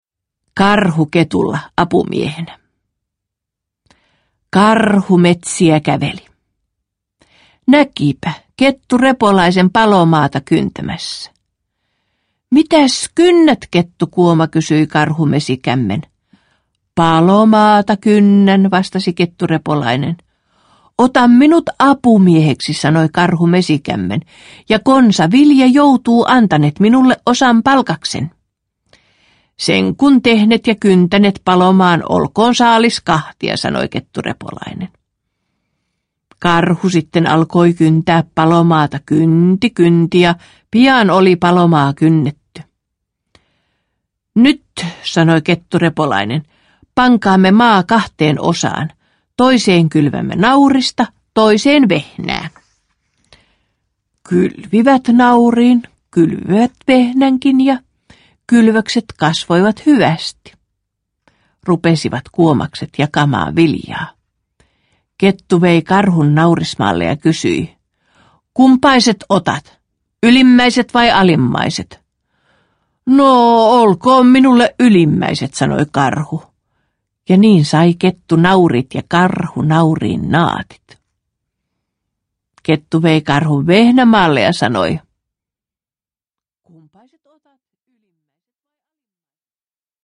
Iloisia eläinsatuja – Ljudbok – Laddas ner